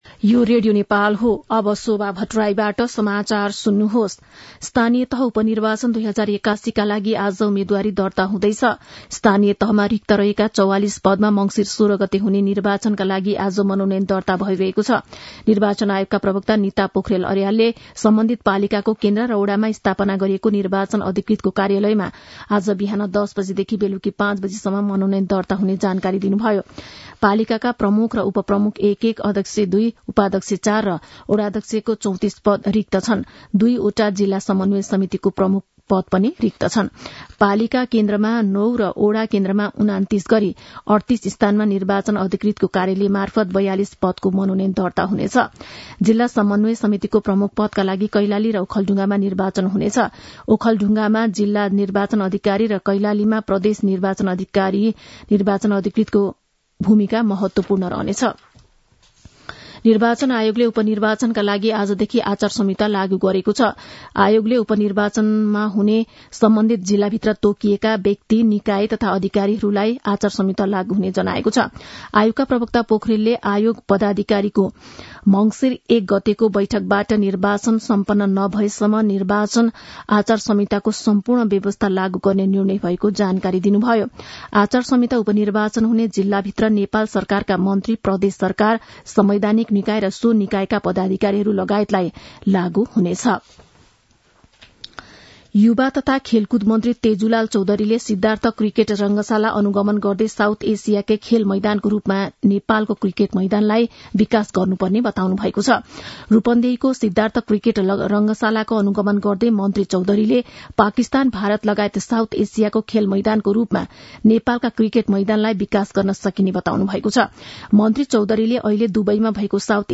मध्यान्ह १२ बजेको नेपाली समाचार : ३ मंसिर , २०८१